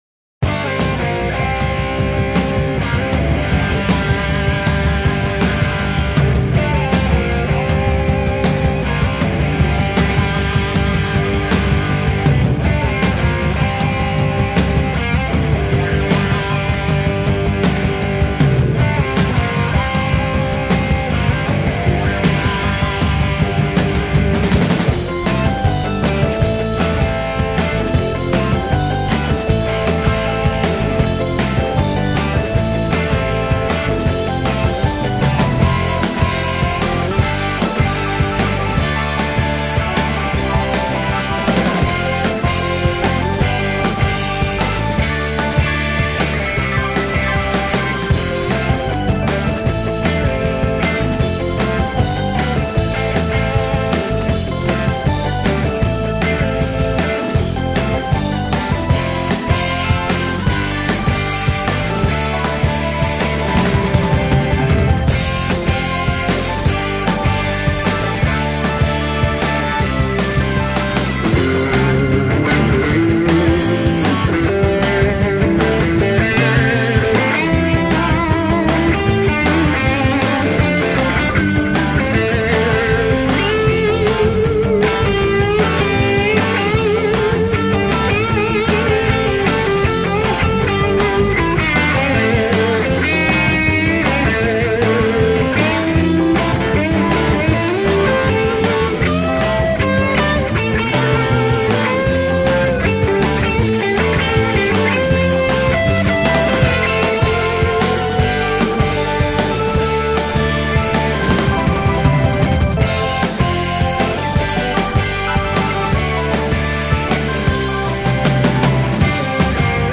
Guitar and Vocals
Bass and Vocal Harmony
Keyboards
Drums / Percussion